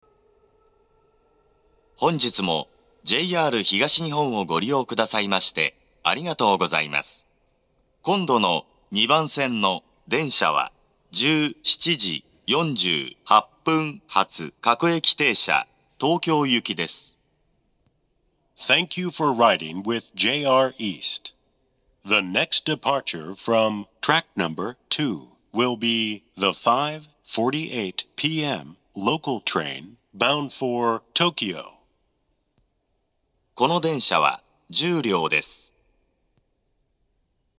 発車メロディー（ＪＲＥ-ＩＫＳＴ-０0１-0
加えて到着放送の鳴動が遅めで、発車メロディーに被りやすいです。
hatchobori2bansen-jihatu2.mp3